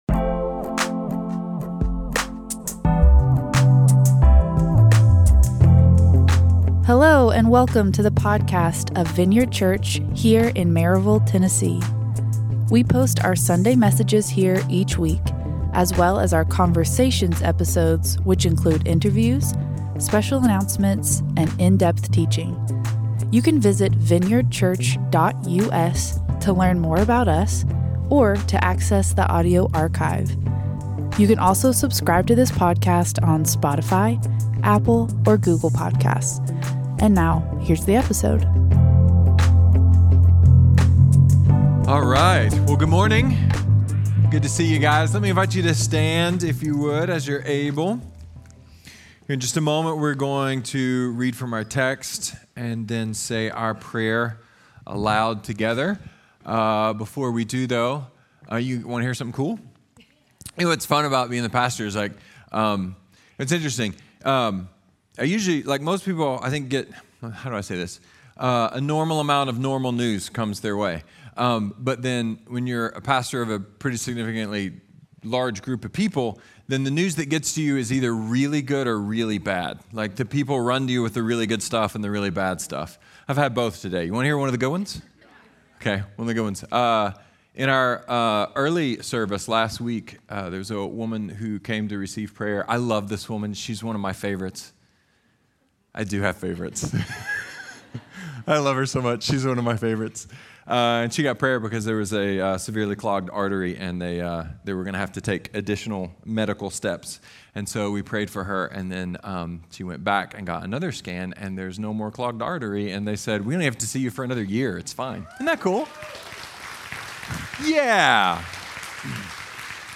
A sermon about why the simplest thing can be the hardest thing.